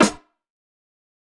TC3Snare2.wav